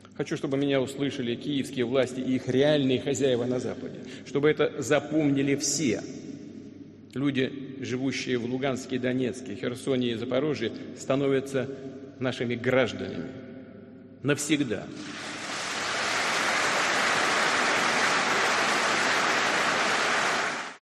"El colapso de la hegemonía occidental que ha comenzado ya no tiene camino atrás", ha dicho el mandatario ruso en un discurso previo a la firma de los tratados de anexión con cuatro regiones ucranianas en la sala San Jorge del Gran Palacio del Kremlin.